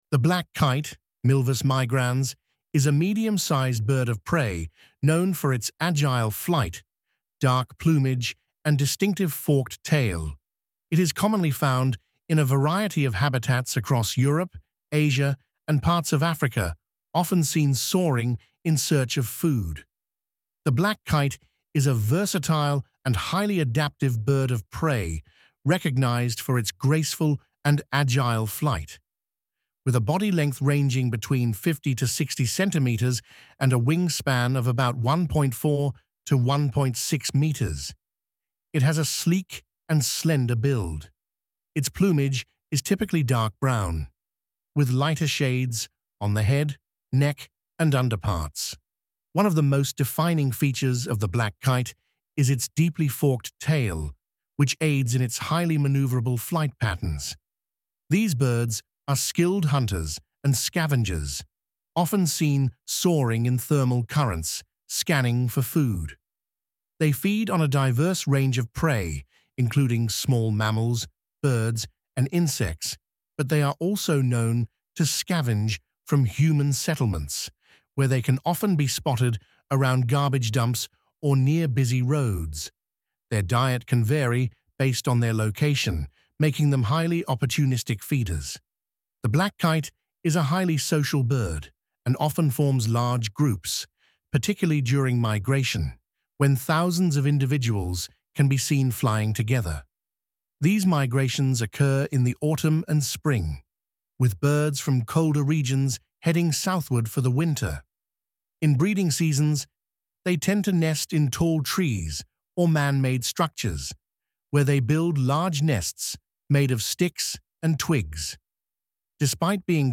Black Kite
Its vocalizations include a variety of calls, often described as harsh and chattering, which are used in communication, especially during nesting or when in flight.
blak_kite001.mp3